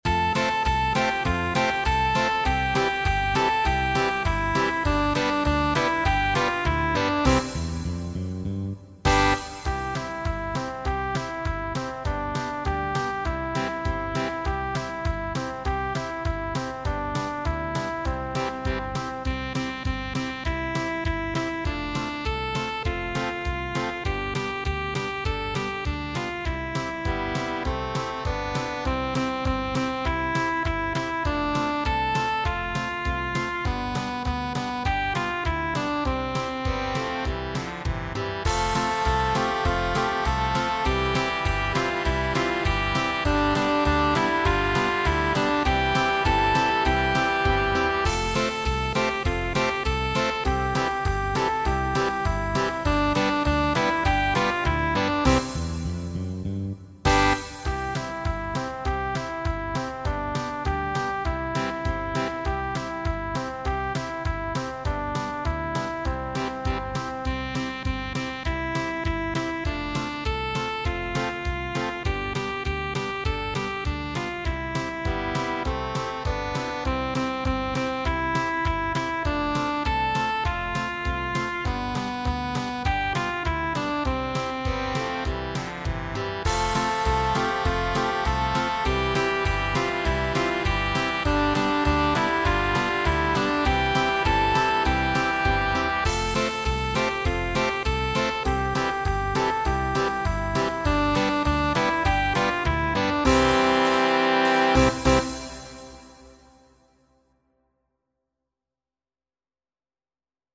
V-part
Backing track